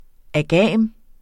Udtale [ aˈgæˀm ]